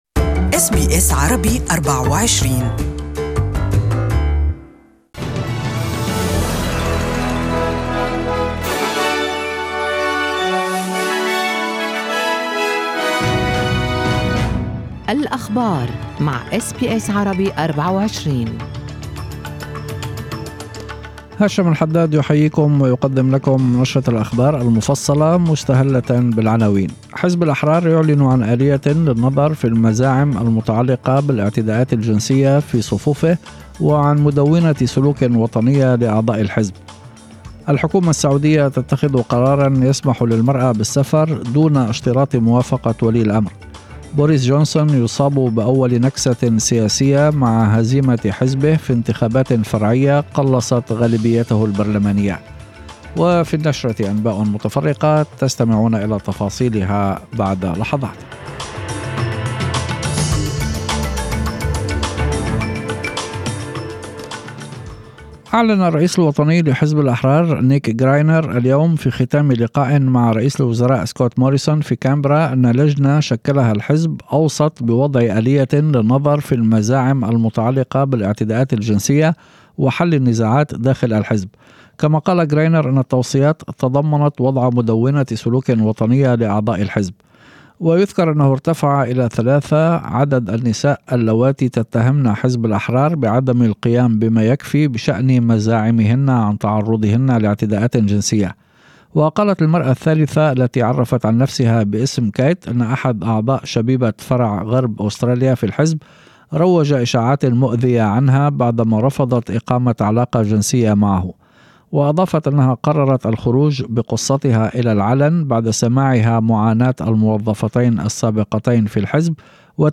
Evening News: Librals to investigate sexual harassment allegations